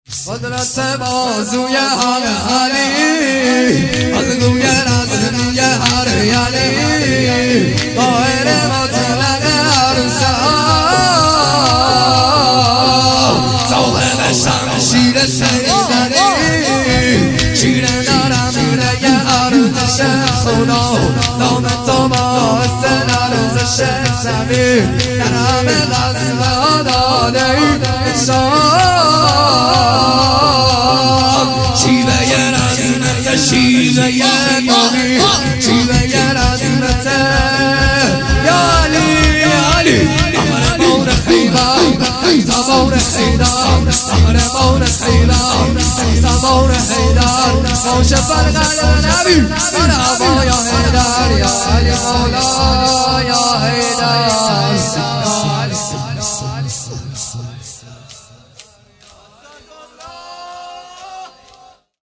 شور طوفانی قدرت بازوی حق علی